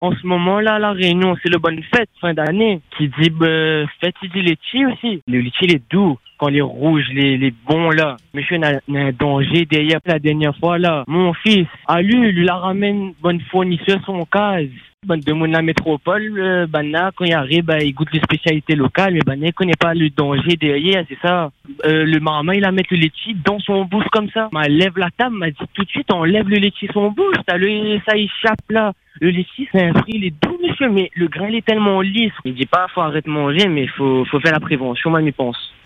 Avec la saison des leetchis, un auditeur a tenu à lancer un rappel essentiel de prévention. Un geste simple, mais qui peut littéralement sauver une vie : toujours vérifier qu’il n’y a plus de grain avant de donner un leetchi à un enfant.